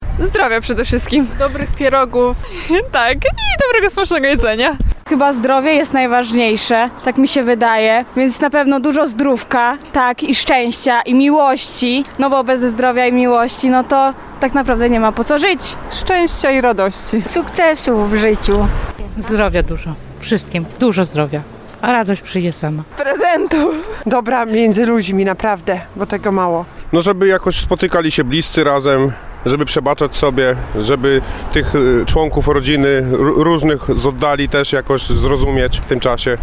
Spytaliśmy mieszkańców regionu: